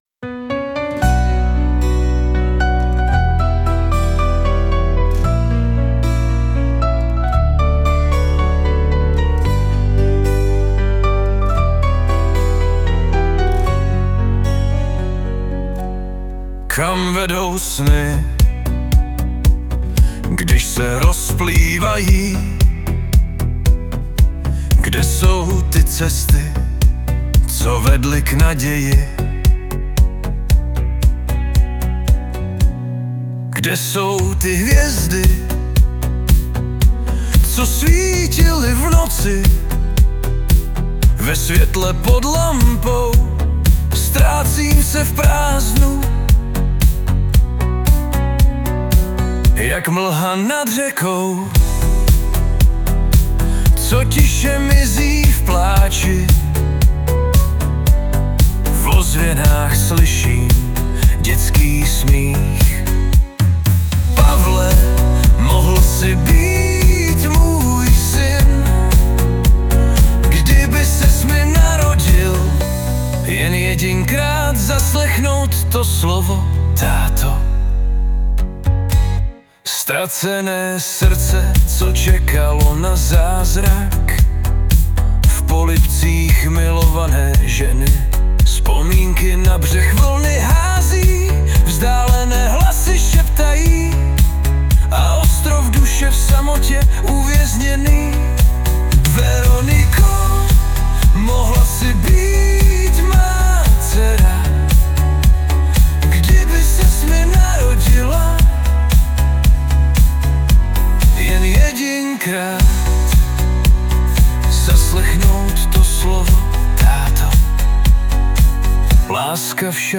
2025 & Hudba, zpěv a obrázek: AI